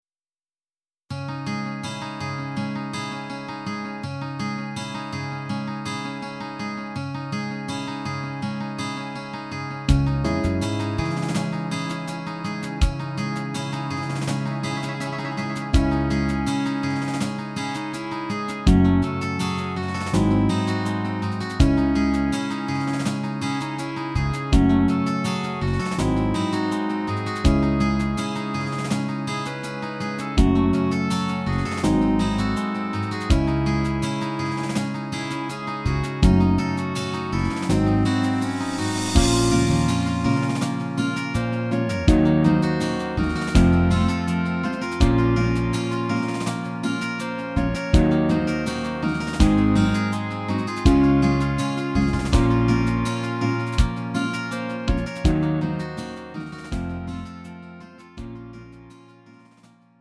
음정 -1키 4:45
장르 가요 구분 Lite MR